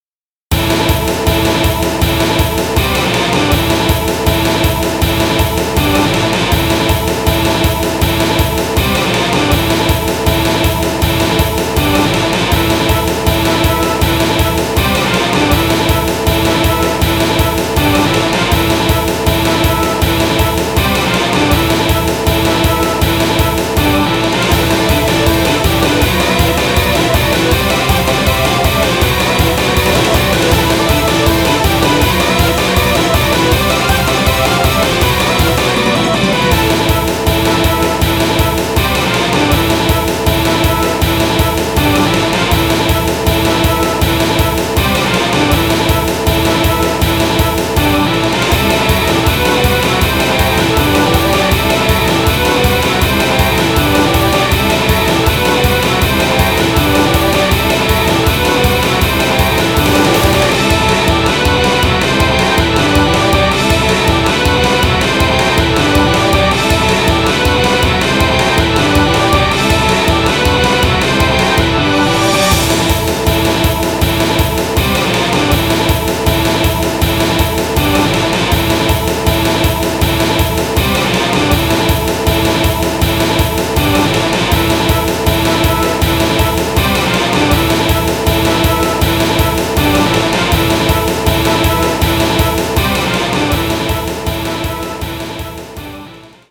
新音源になってからの初メタル風戦闘曲